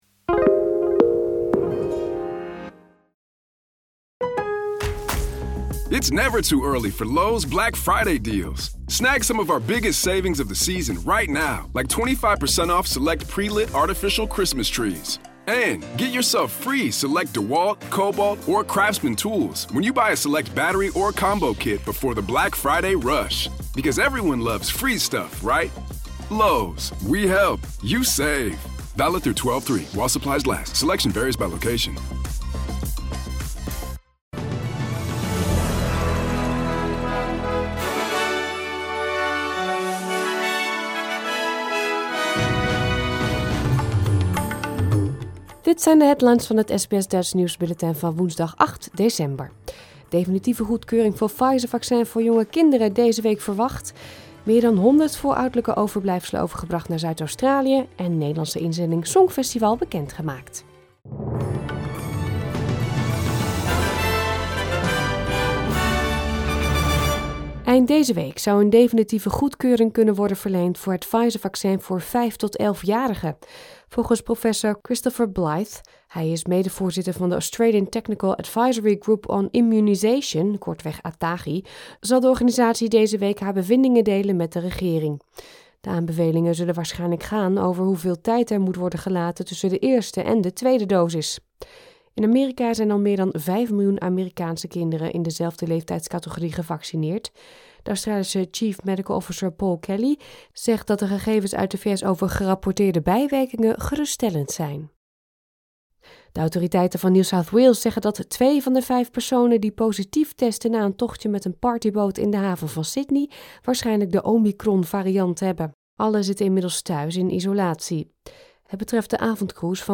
Nederlands / Australisch SBS Dutch nieuwsbulletin van woensdag 8 december 2021